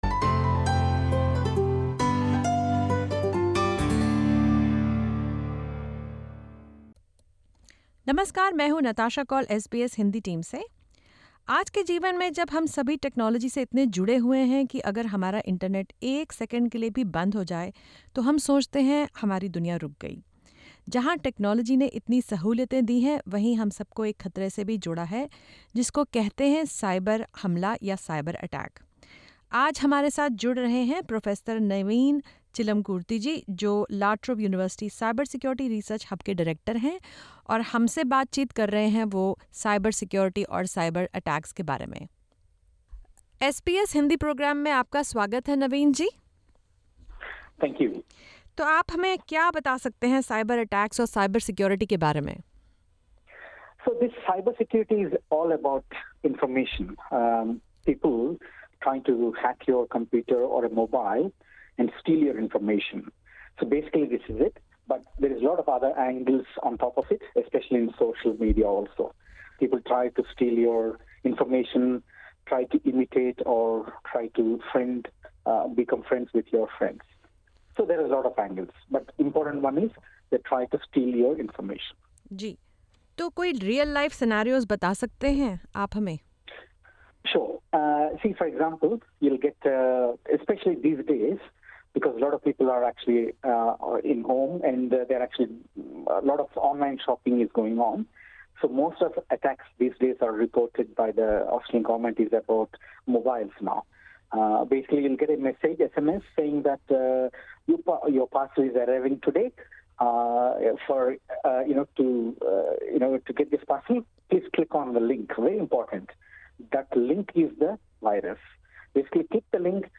No one is excluded from the risk of a cyberattack: says a cyber security expert